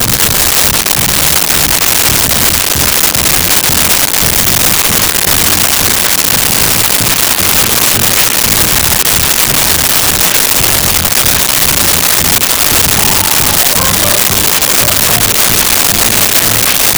Background Tv3
BACKGROUND TV3.wav